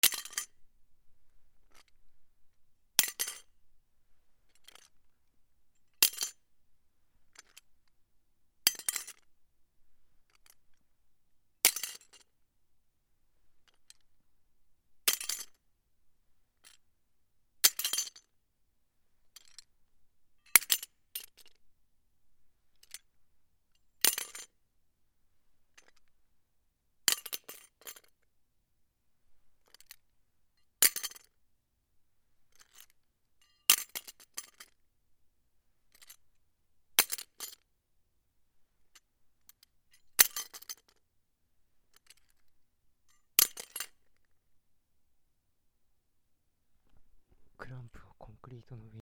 クランプをアスファルトの上に落とす
/ J｜フォーリー(布ずれ・動作) / J-10 ｜転ぶ　落ちる